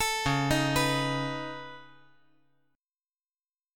Listen to C#7sus2#5 strummed